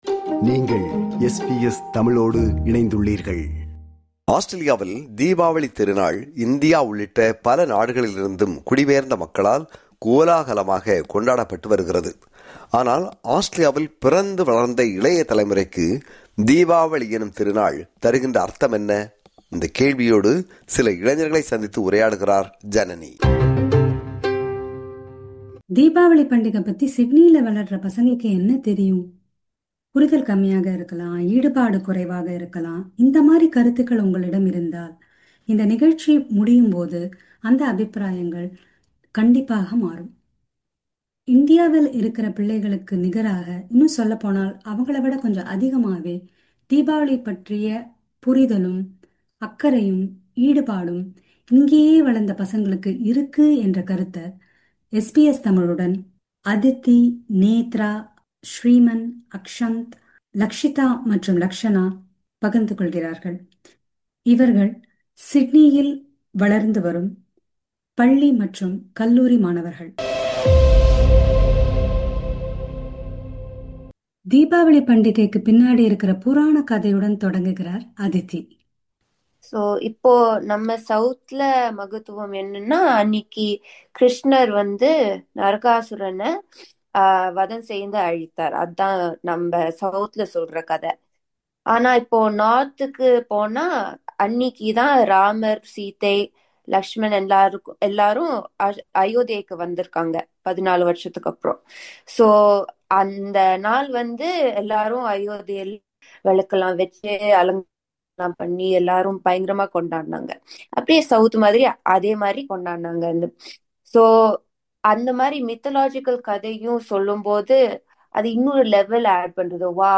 ஆனால் ஆஸ்திரேலியாவில் பிறந்து வளர்ந்த இளைய தலைமுறைக்கு தீபாவளி தரும் அர்த்தம் என்ன? இந்த கேள்வியோடு சில இளைஞர்களை சந்தித்து உரையாடுகிறார்